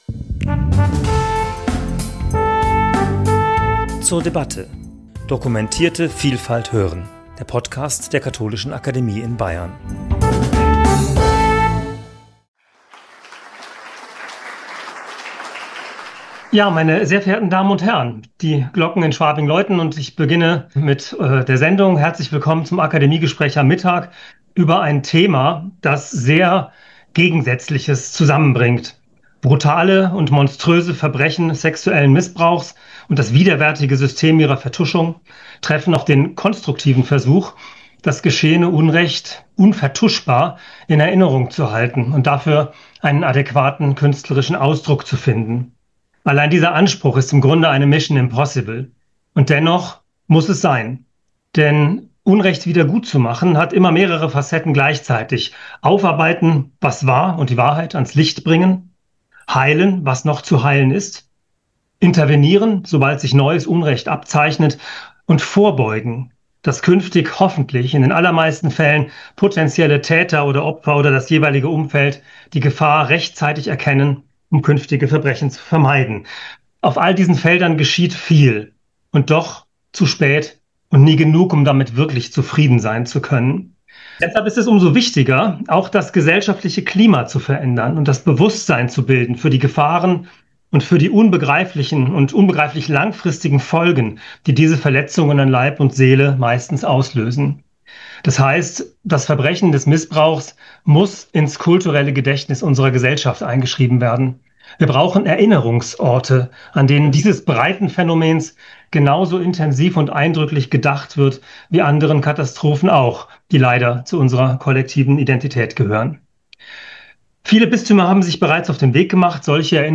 Gespräch zum Thema 'Missbrauch aufdecken und erinnern: das Paderborner Mahnmal' ~ zur debatte Podcast